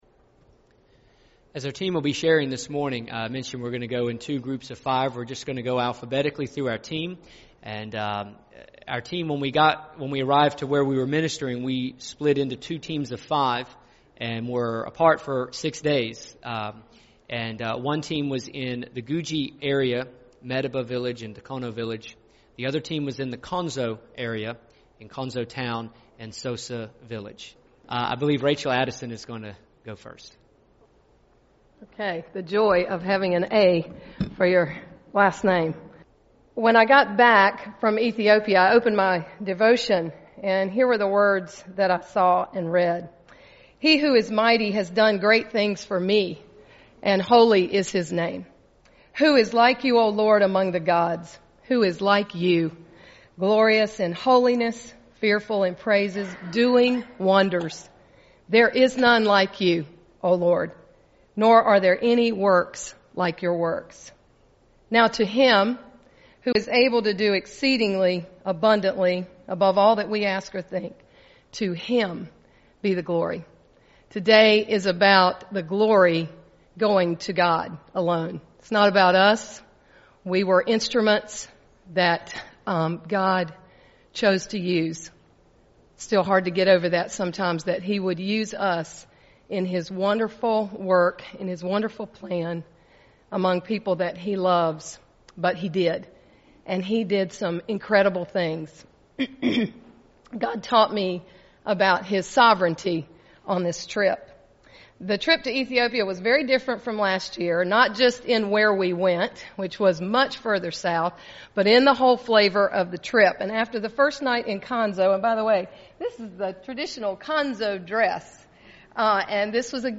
Our team of 10 had an amazing time in Ethiopia in July. Here is the audio of our report to our church.
ethiopia2013-presentation.mp3